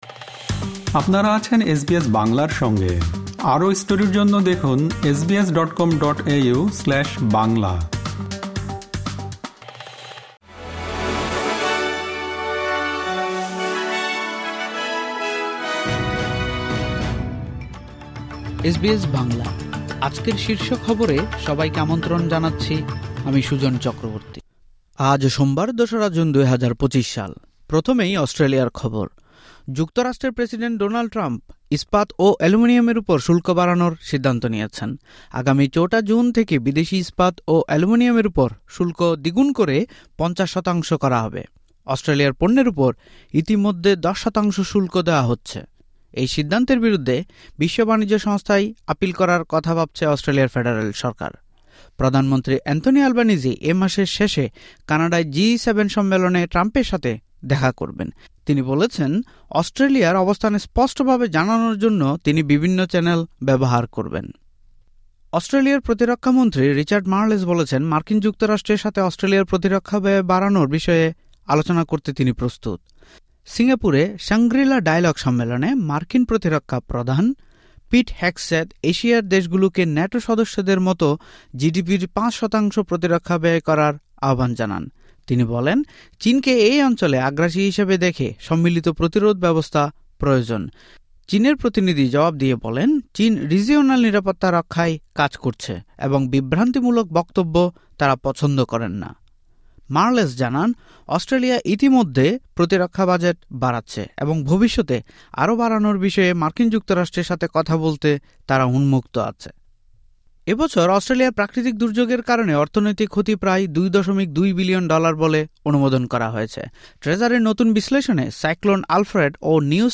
এসবিএস বাংলা শীর্ষ খবর: ০২ জুন ২০২৫